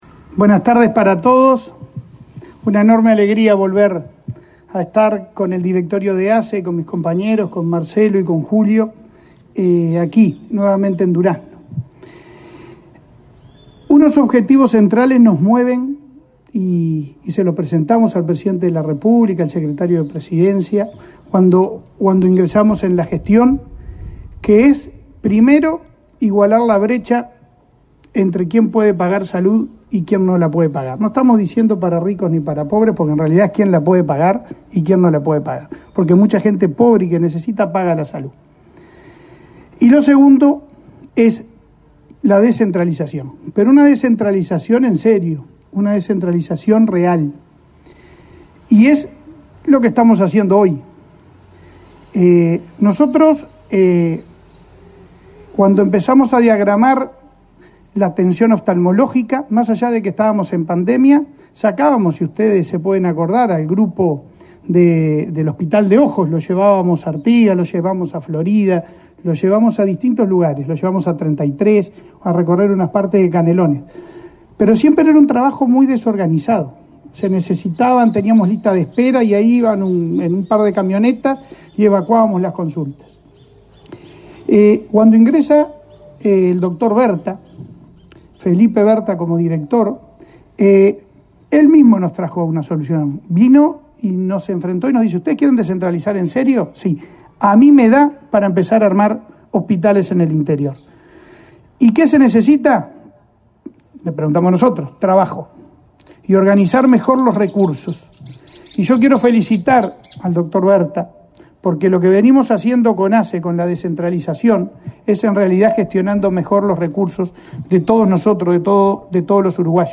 Conferencia de prensa por la inauguración de la sede centro del Hospital Especializado de Ojos, en Durazno